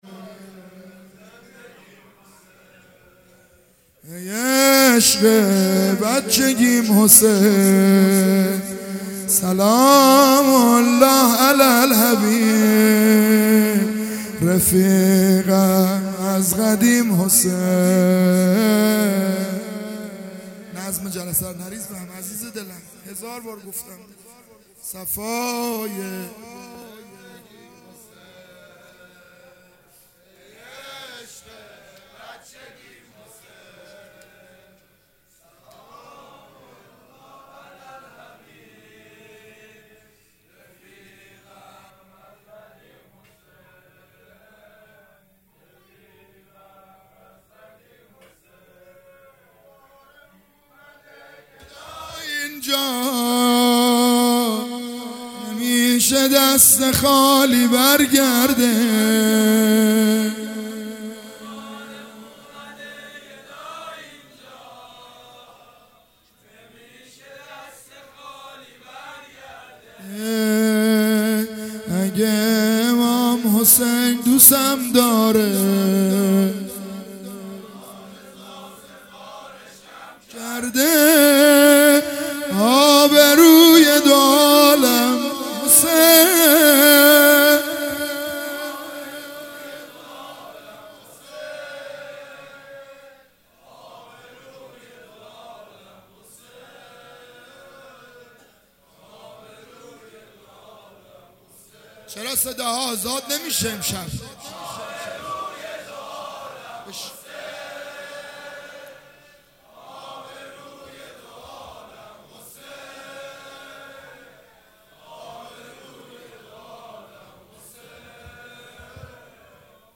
محرم 98 شب تاسوعا - زمینه - ای عشق بچگیم حسین
دهه اول محرم الحرام 1441